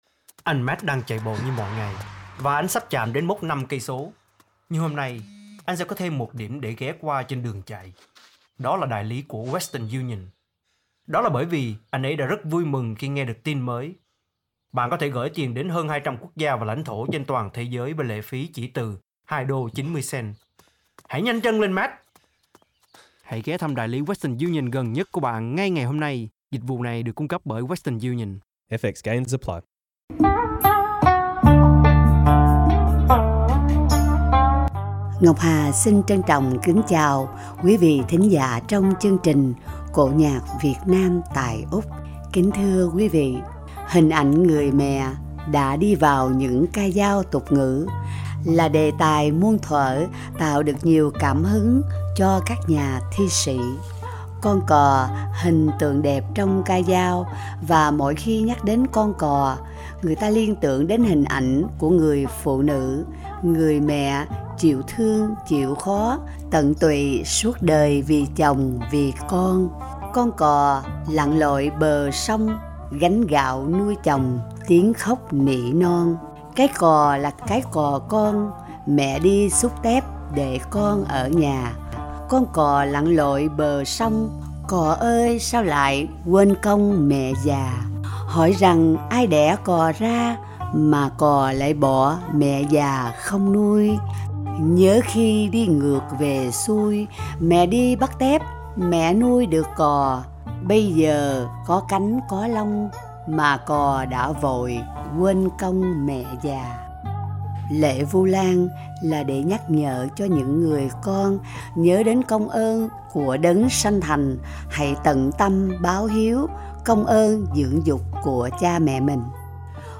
ca cảnh